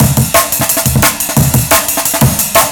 rs_-_another_amen.wav